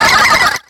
Cri de Spinda dans Pokémon X et Y.